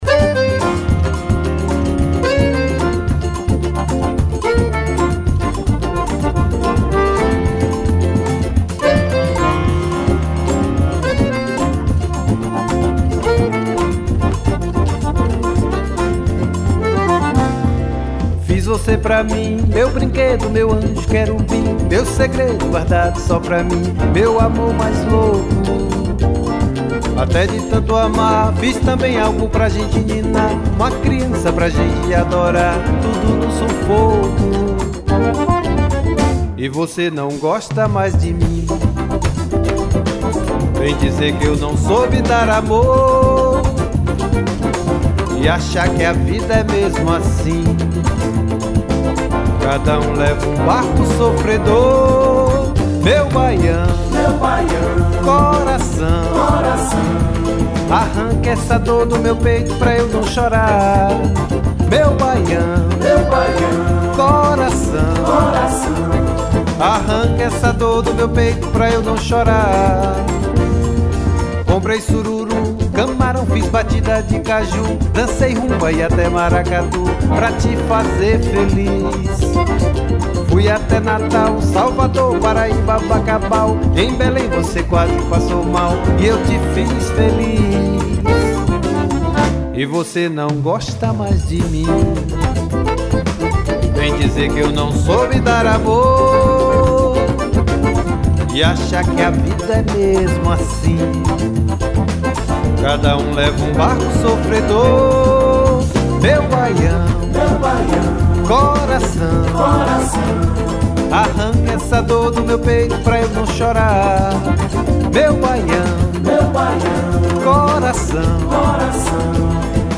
1078   02:03:00   Faixa:     Forró